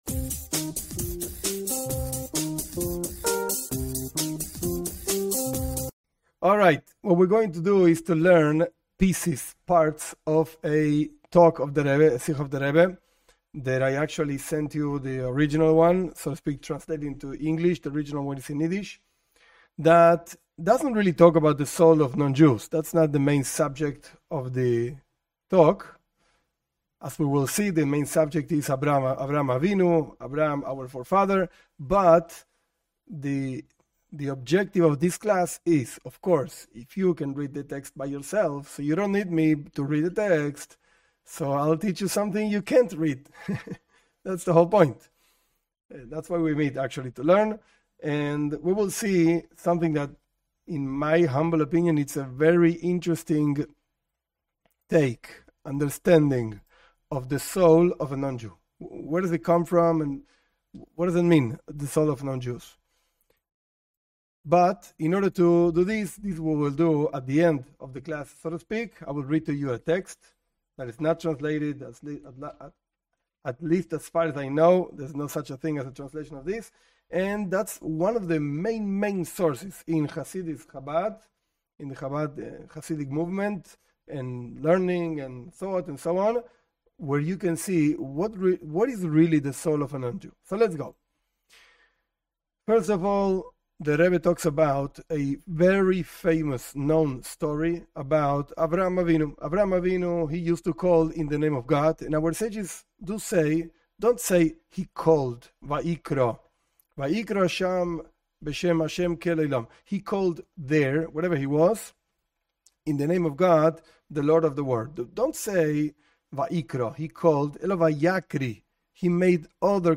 This class is based on a talk by the Rebbe explaining a story of Abraham and his guests. Abraham would force them to thank G-d after giving them food.